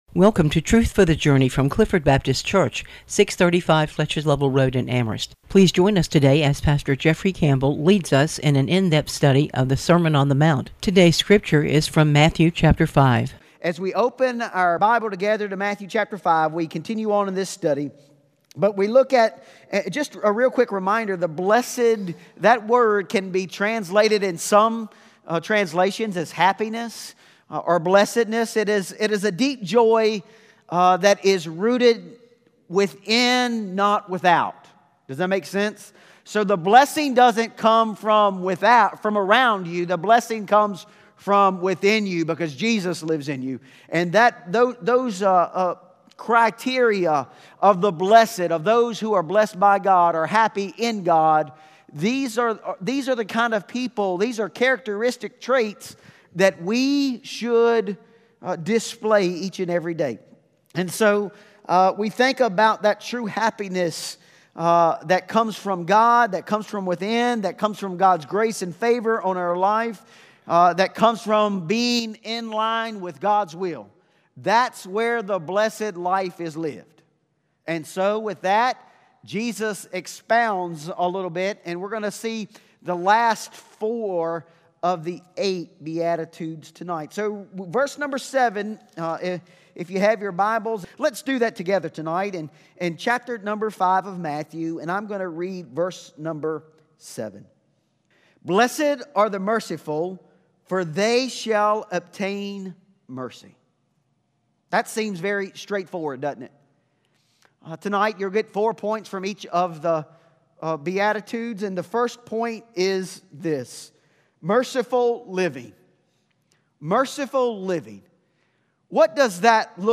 Clifford Baptist Sermon on the Mount: The Blessed - Part 2 Aug 18 2025 | 00:33:48 1x 00:00 / 00:33:48 Subscribe Share Spotify RSS Feed Share Link Embed